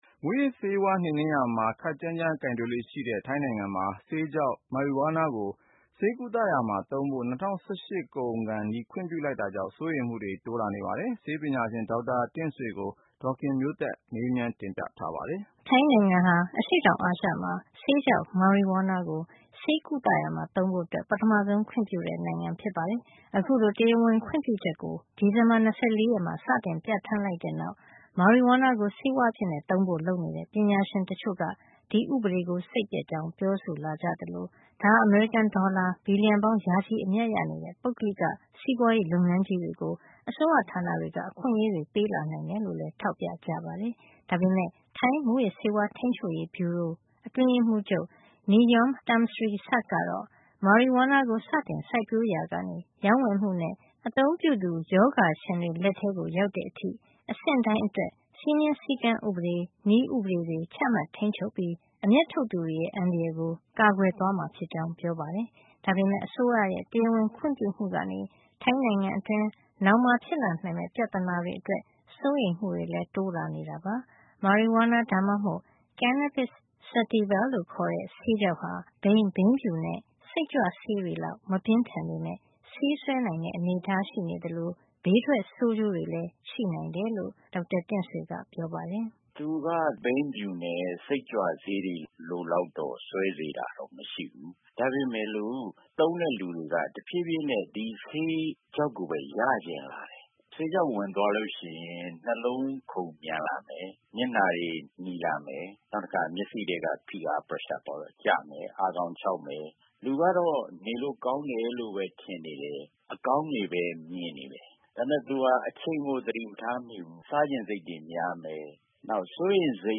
ဆက်သွယ်မေးမြန်းပြီး တင်ပြထားပါတယ်။